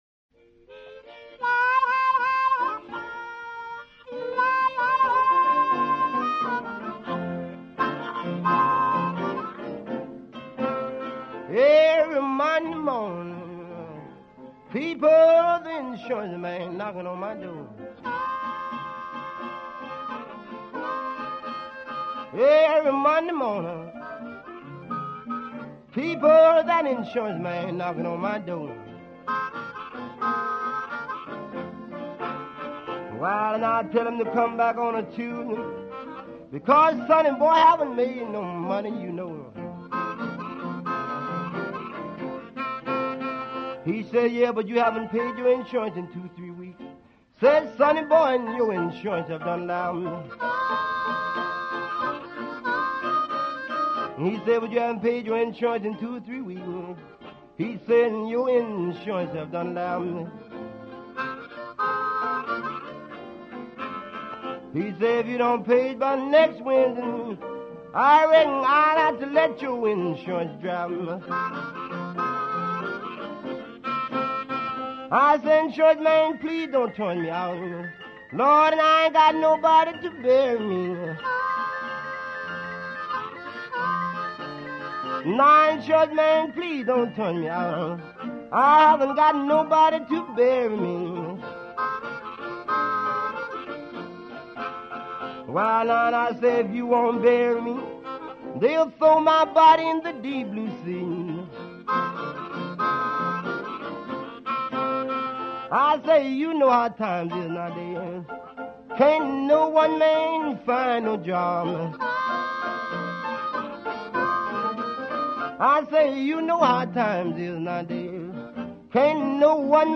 Vocals, Harmonica
Guitar
Piano